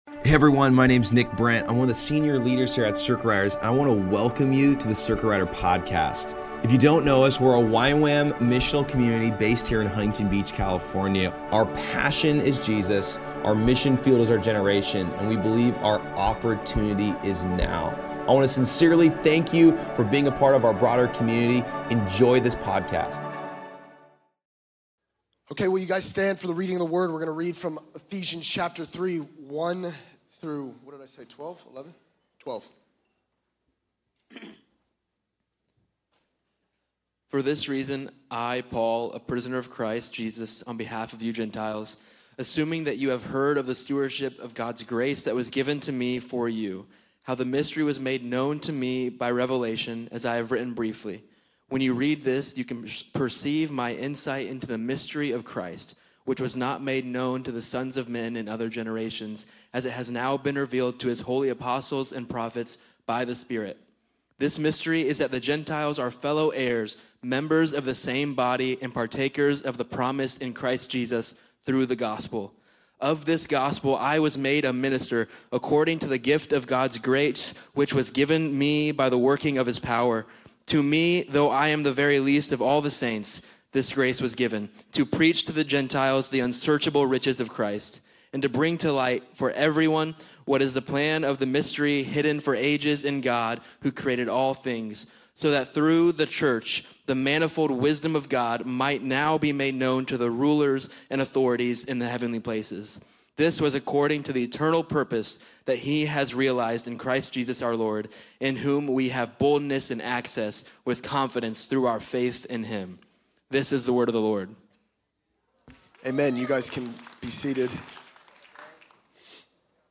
Location Costa Mesa